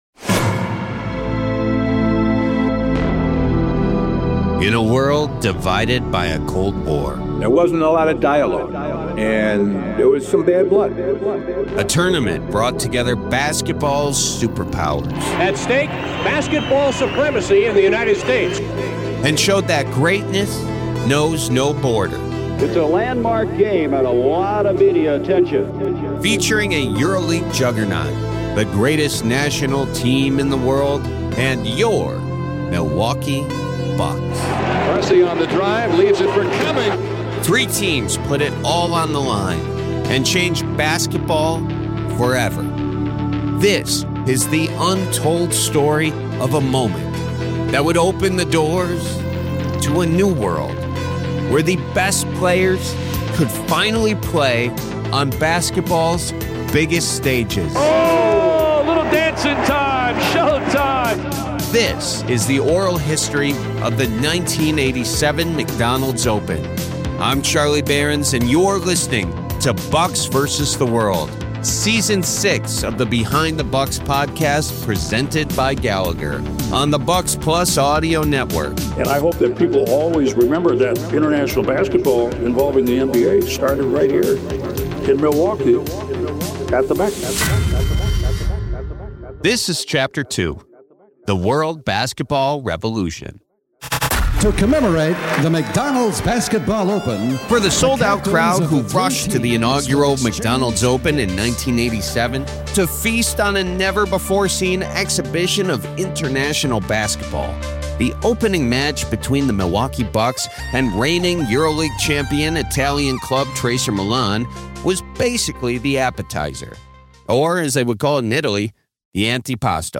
The opening game of the 1987 McDonald’s Open revealed something no one was prepared for. Narrated by Charlie Berens, Episode 2 of Bucks vs. the World explores how the Milwaukee Bucks’ matchup with EuroLeague champion Tracer Milan sent shockwaves through international basketball, forcing the NBA, the media, and fans across Europe to reconsider what was possible.
The story then moves behind the Iron Curtain, introducing the Soviet Union national team as they arrive in Milwaukee. Through firsthand accounts from players, coaches, and journalists, the episode examines the challenging political realities that shaped the lives of the USSR’s stars and their legendary head coach — and how anticipation for the tournament’s final game grew into something much bigger than basketball.